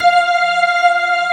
Index of /90_sSampleCDs/AKAI S6000 CD-ROM - Volume 1/VOCAL_ORGAN/POWER_ORGAN
P-ORG2  F5-S.WAV